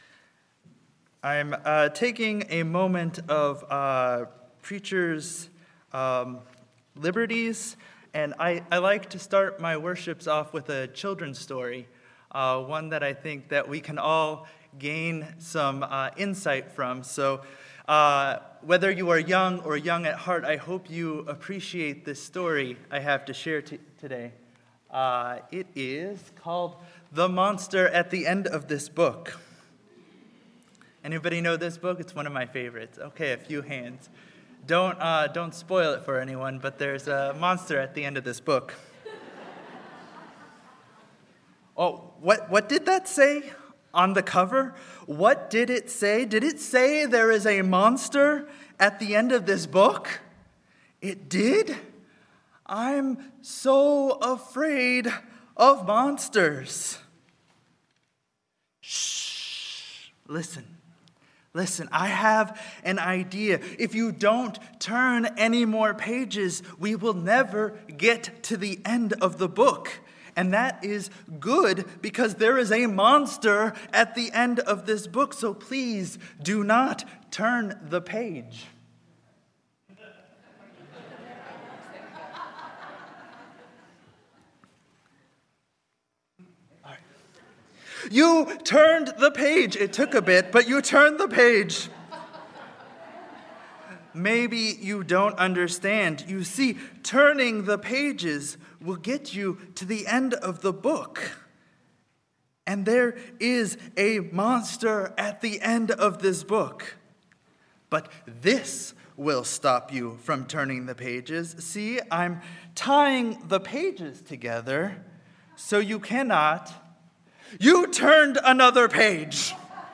Audio notes: This audio track comprises a reading followed by a sermon. The sermon begins at 4 minutes and 15 seconds into the track and continues until the end.
Sermon-New-Endings-New-Opportunities.mp3